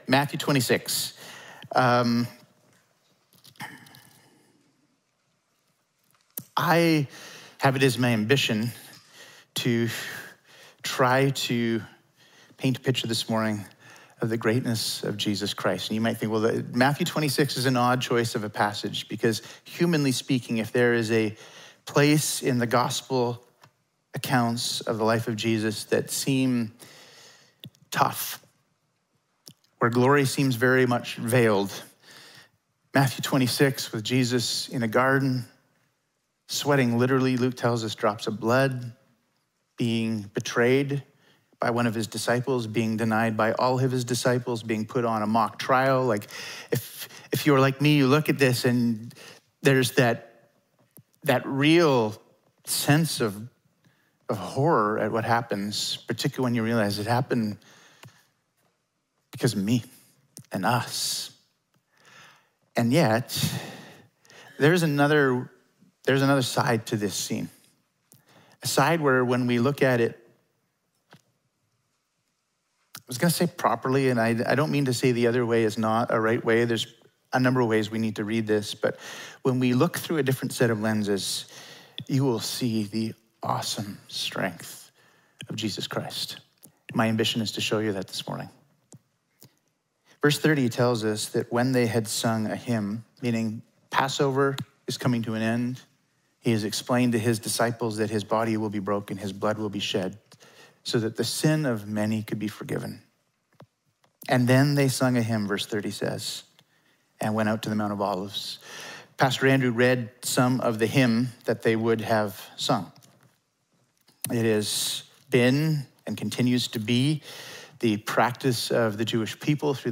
Sermons | Emmanuel Baptist Church